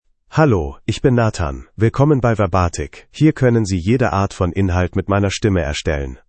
MaleGerman (Germany)
NathanMale German AI voice
Nathan is a male AI voice for German (Germany).
Voice sample
Nathan delivers clear pronunciation with authentic Germany German intonation, making your content sound professionally produced.